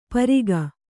♪ pariga